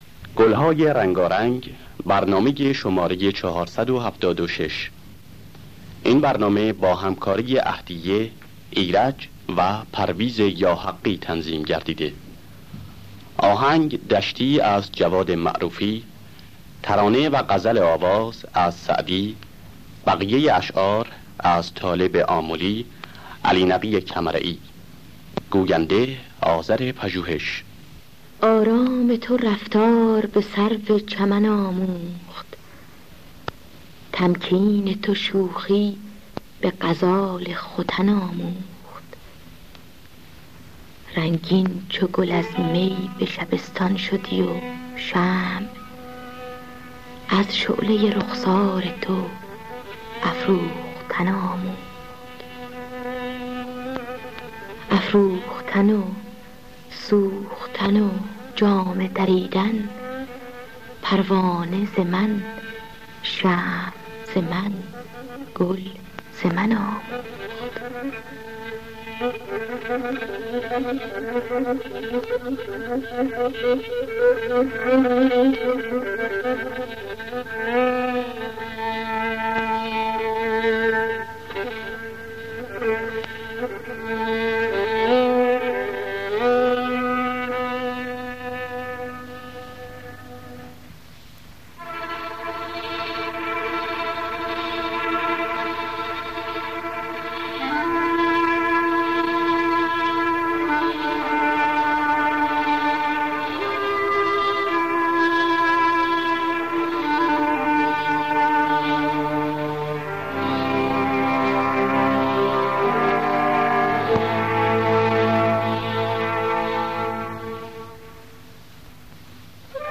در دستگاه دشتی